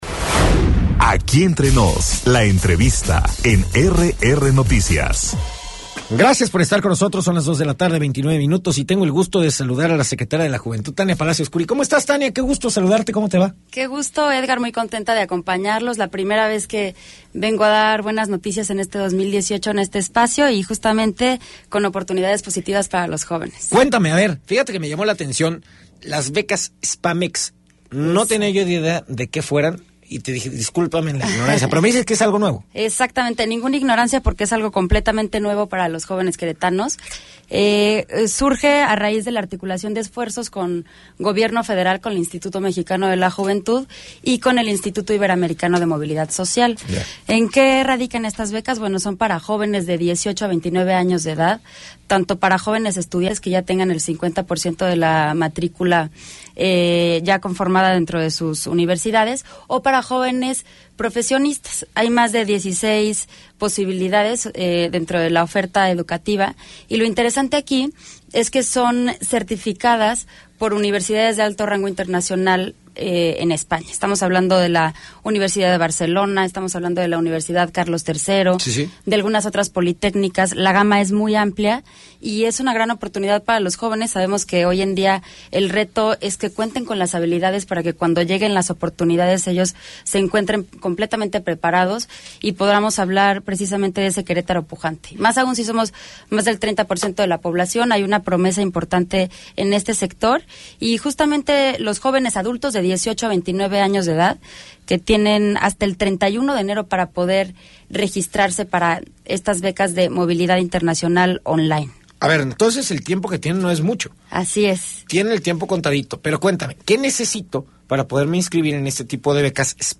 Entrevista con la Secretaria de la Juventud, Tania Palacios Kuri - RR Noticias